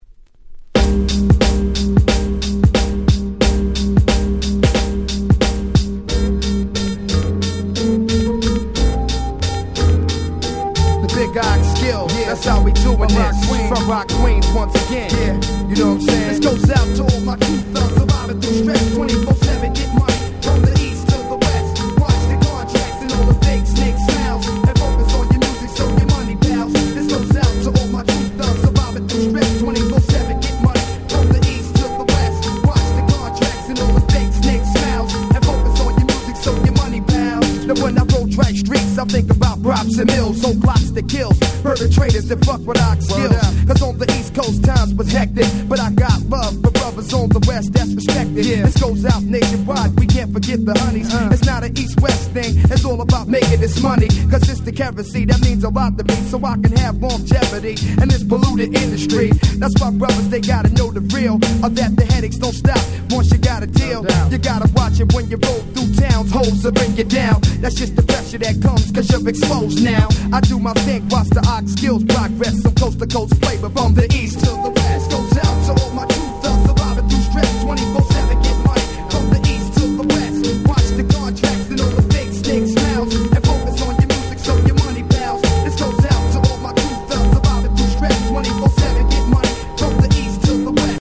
90's アンダーグラウンド アングラ　Boom Bap ブーンバップ